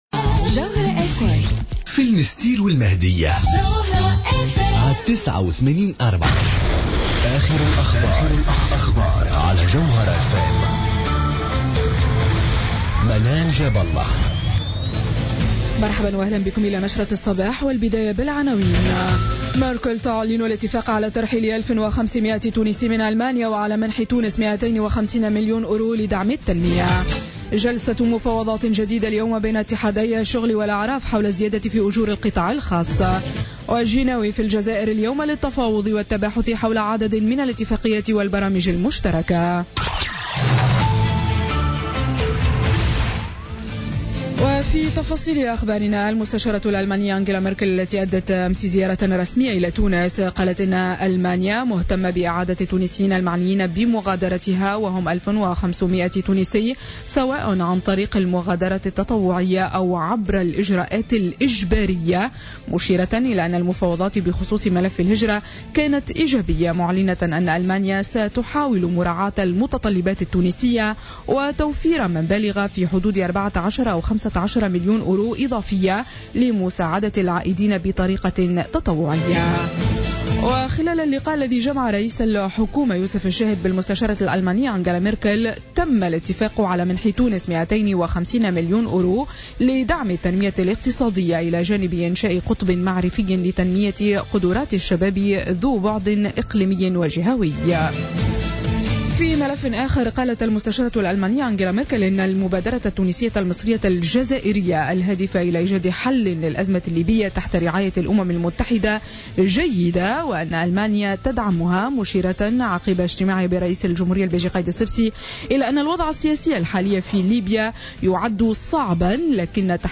نشرة أخبار السابعة صباحا ليوم السبت 4 مارس 2017